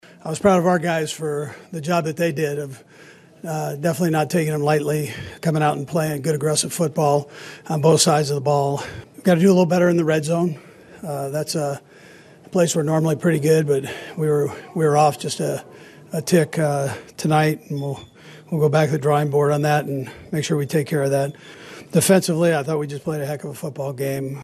Chiefs Coach Andy Reid was glad to get the win.
11-28-andy-reid.mp3